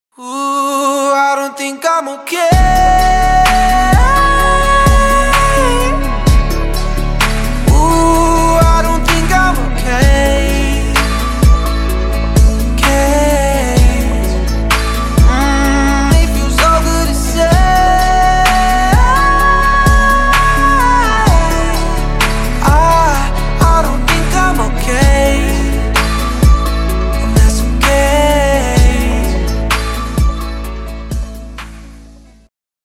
Женский голос Vocal trance